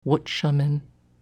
Wutshamin pronunciation only